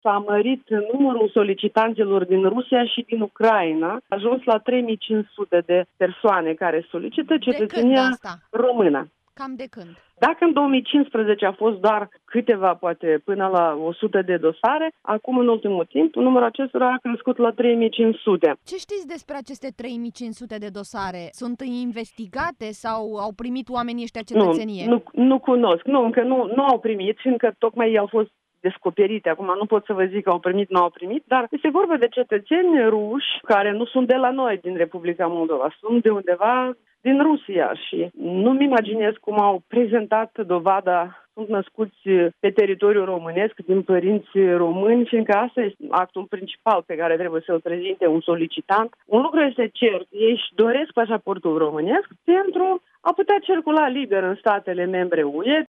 Ana Guţu spune că nu mai puţin de 3.500 de dosare au probleme: